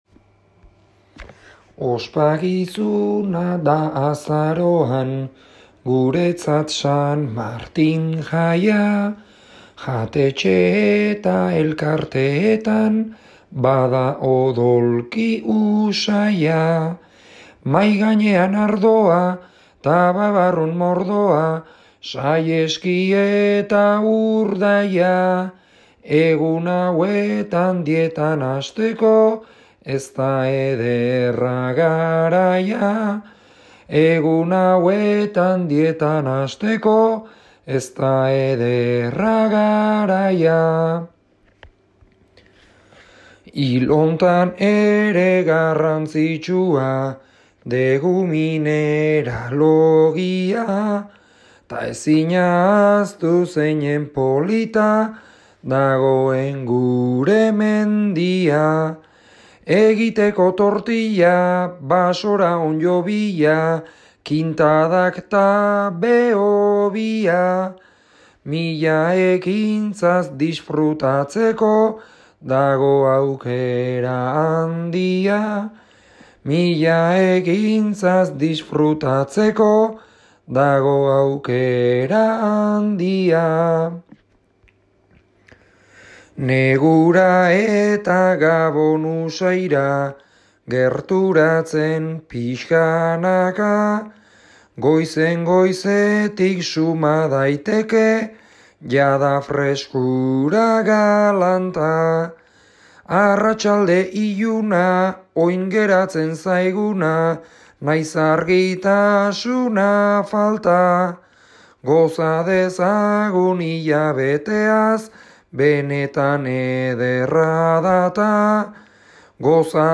bertso sorta